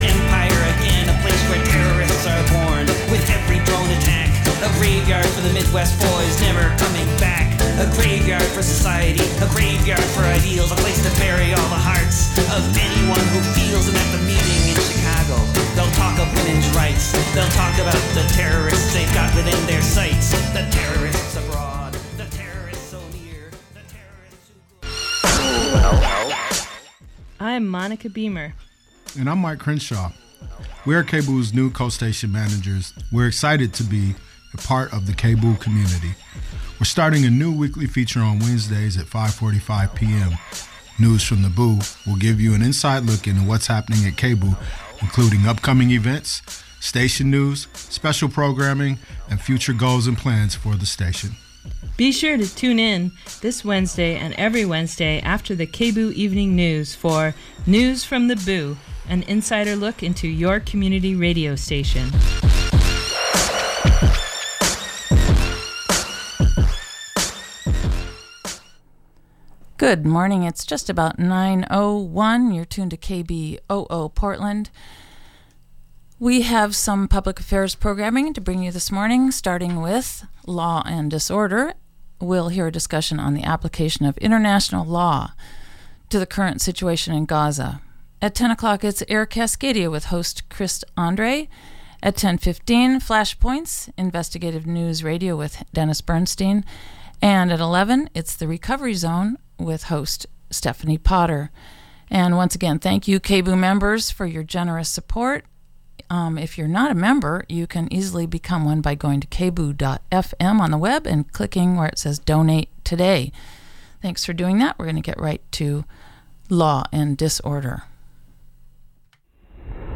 Download audio file Three of the top progressive lawyers and activists in the growing fight for civil liberties, civil rights and human rights host a weekly/one hour talk radio show, Law & Disorder.